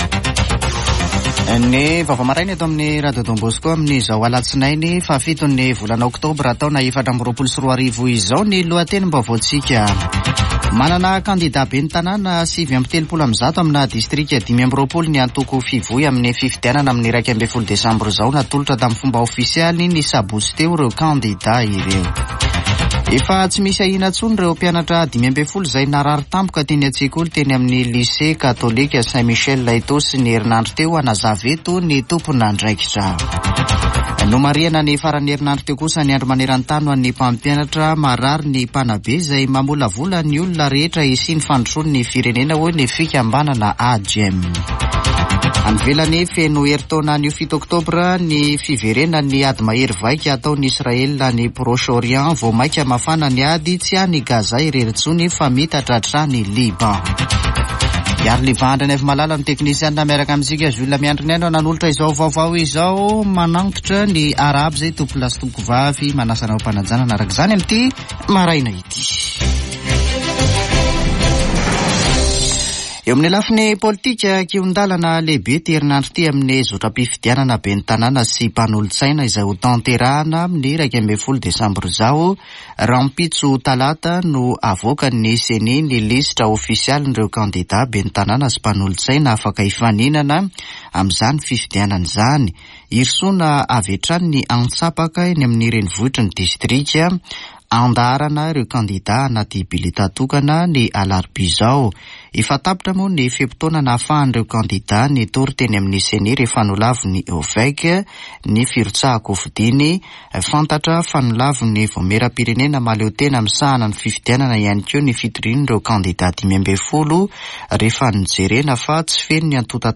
[Vaovao maraina] Alatsinainy 7 oktobra 2024